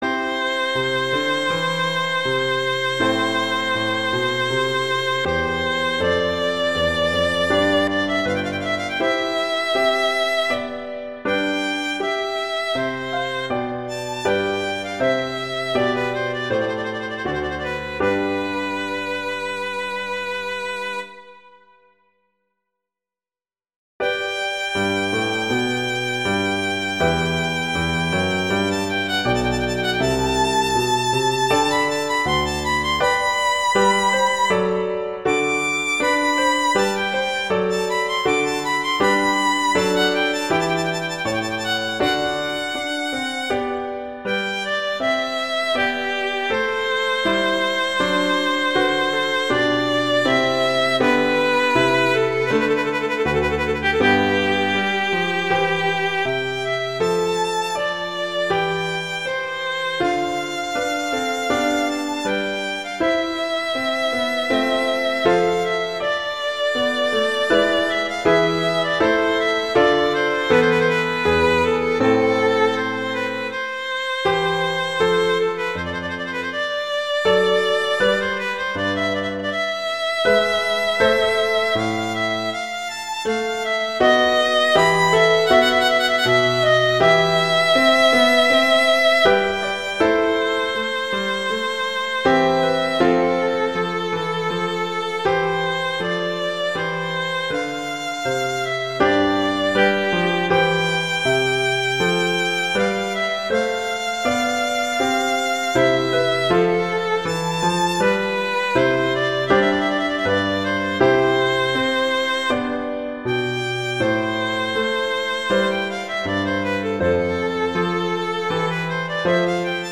violin and piano
classical
Adagio